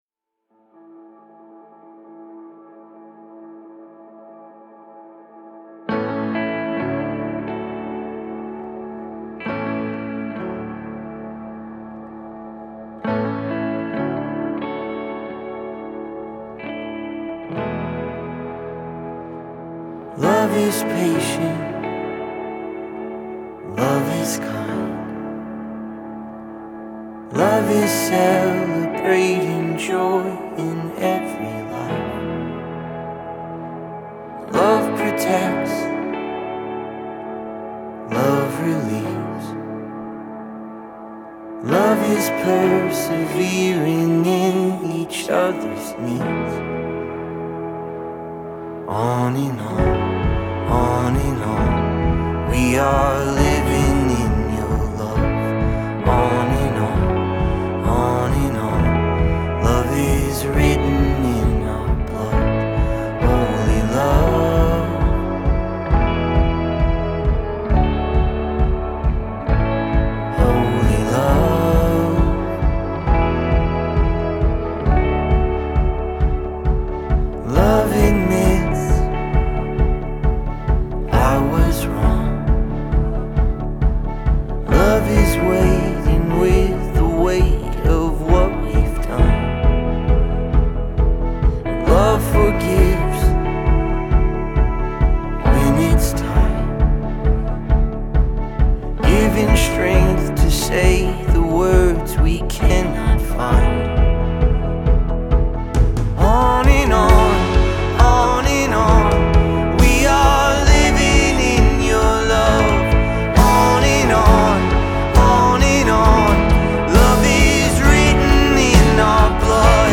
464 просмотра 328 прослушиваний 29 скачиваний BPM: 62